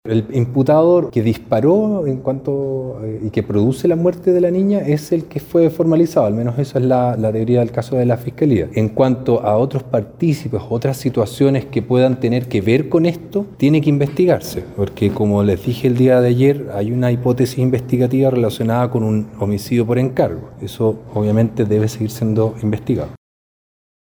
El fiscal de ECOH, Paul Martinson, señaló que se maneja la hipótesis de un homicidio por encargo. Sin embargo, el acusado habría tenido la orden de disparar contra otro objetivo, con un vehículo de similares características.